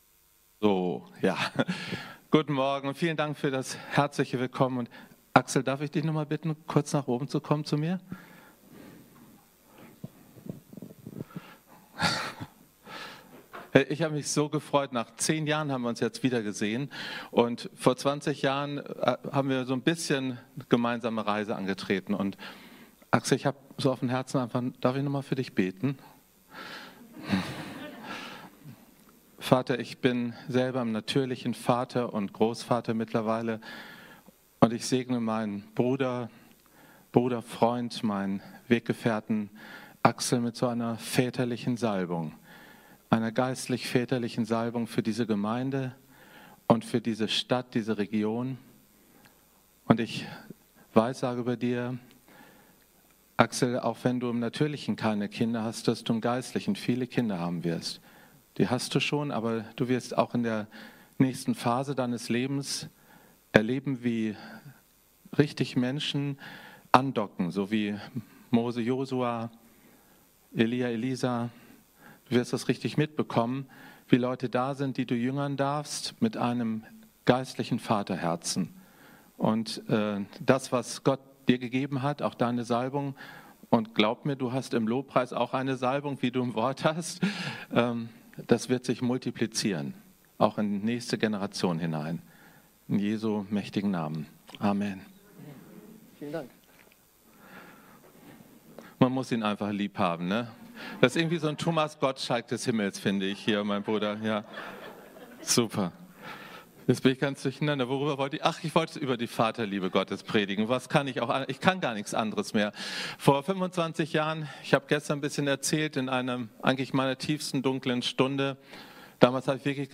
Vaterherz Wochenende - Gottesdienst ~ Predigten der OASIS Kirche Lilienthal Podcast